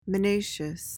PRONUNCIATION:
(mi-NAY-shuhs)